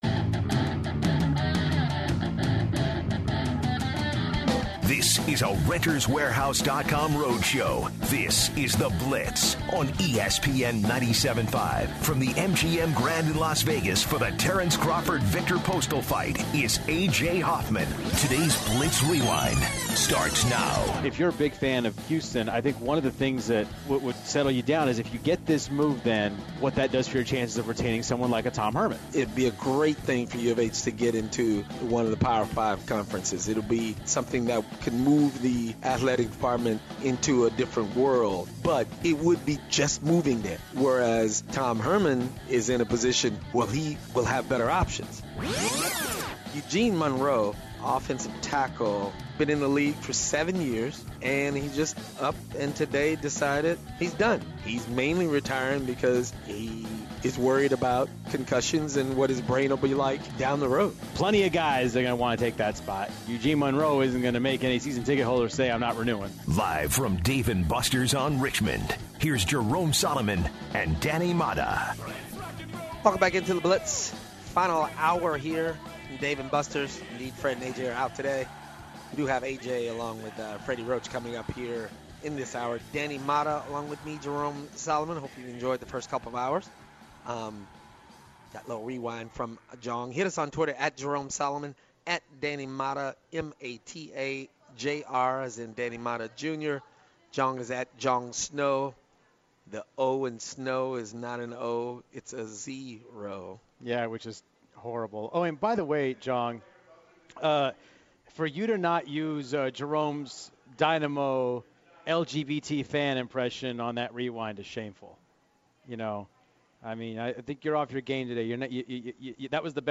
comes back on air from Las Vega to interview Freddy Roach